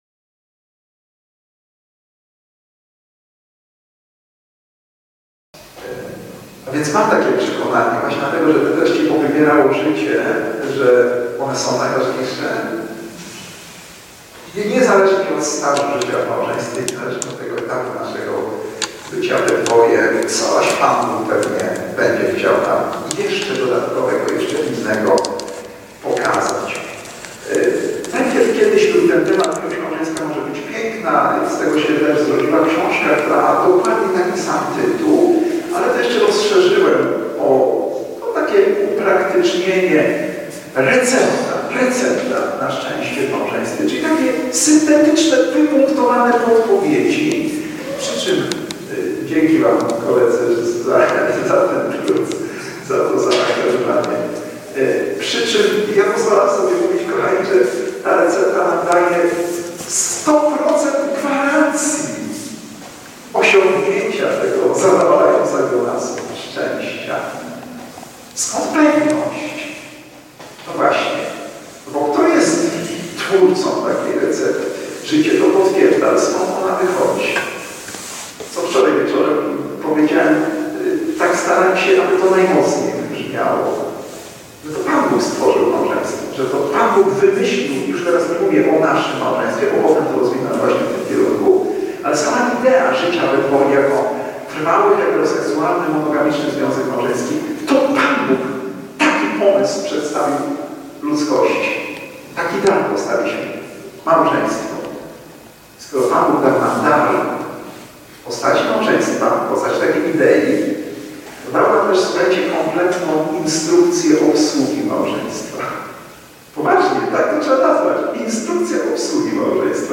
W dniach 22 - 24 października 2021 roku, w naszej parafii odbyły się rekolekcje pod hasłem "Remont Małżeński".